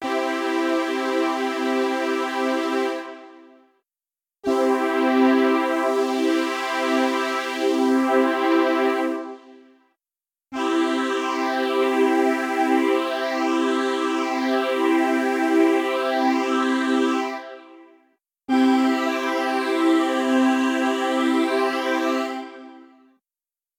Phaser
El phaser se produce cuando se eliminan ciertas componentes frecuenciales de la señal de audio, pero de forma variable con el tiempo.
Se trata de una técnica muy usada por guitarristas, y como se puede escuchar en el siguiente enlace de audio el efecto es bastante similar al Flanger.
Los tonos que se anulan varían con el tiempo, puesto que el filtro también lo hace.
Phasing_effect.wav